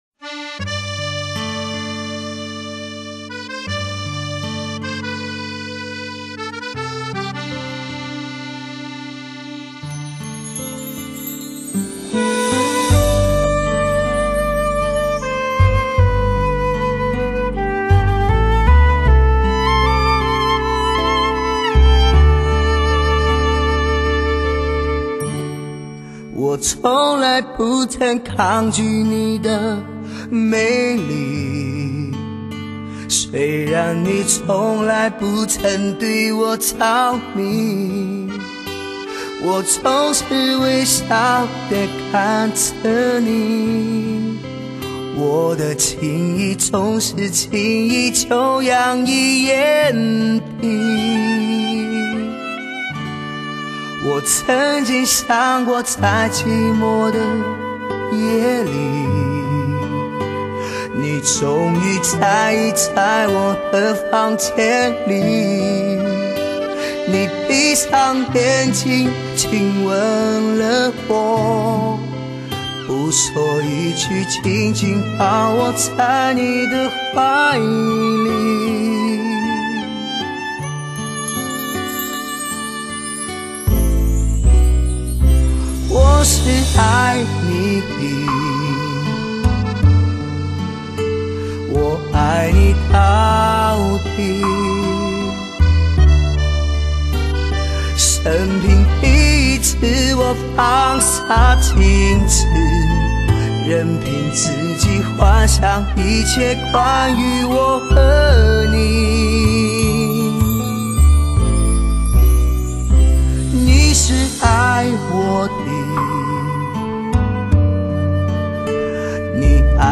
本专辑可谓是尝鲜之作，听这略带粗犷刚阳的美声，
那充满强力和力度的嗓音，索绕不去的情绪灵魂，
而精心的编曲，恰当的乐器烘托，
精彩的录音更让你体会那灼热的情感和不俗的唱功。